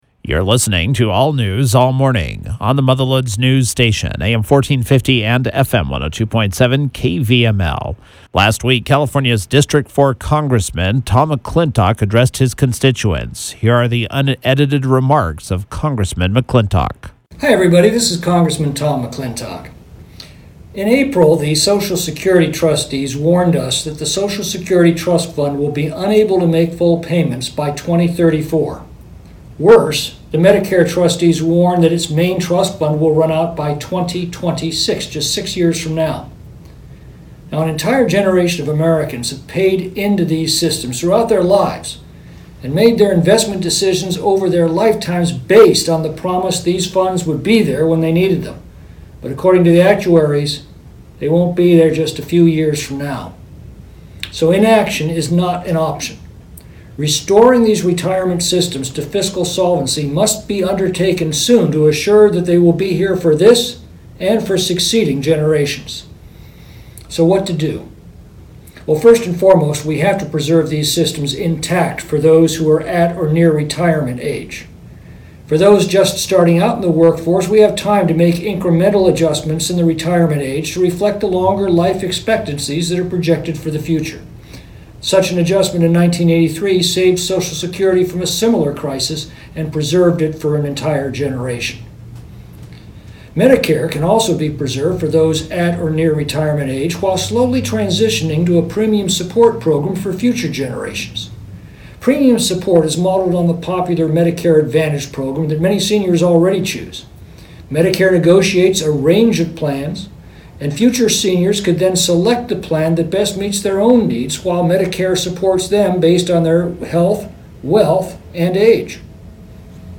McClintock was Friday’s KVML “Newsmaker of the Day”.